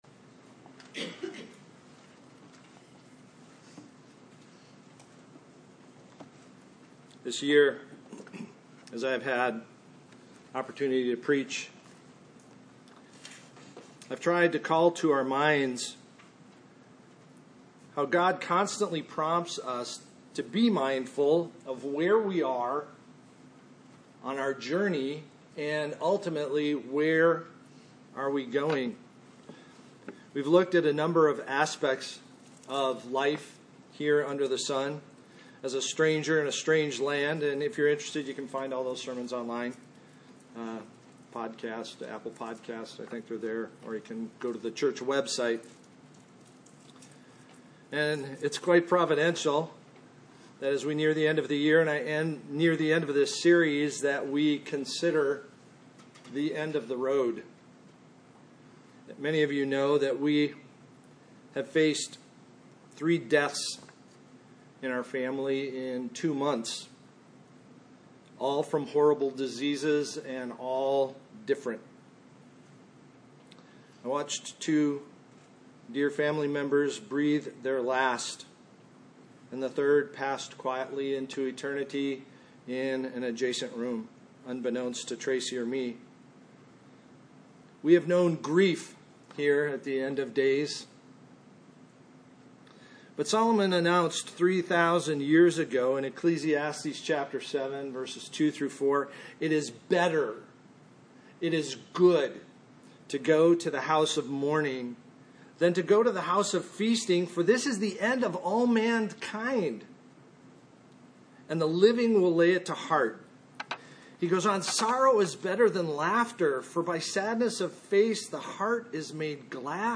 Passage: Revelation 20:11-15, John 11:1-26 Service Type: Sunday Morning Where are you going?